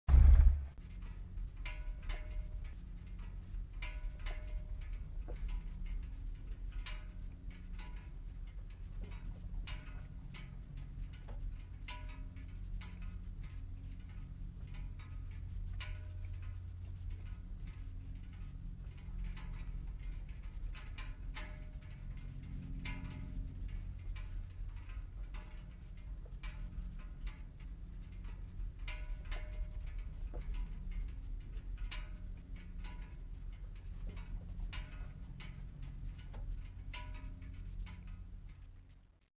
Home gmod sound vehicles tdmcars miscsounds
engine_off2.mp3